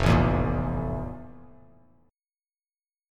FM11 Chord
Listen to FM11 strummed